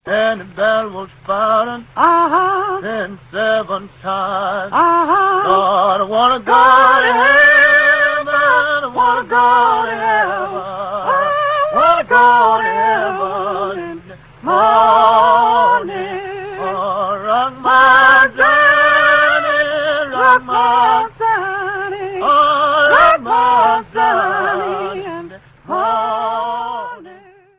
Here, she sings six temporal songs as solos, plus four spirituals.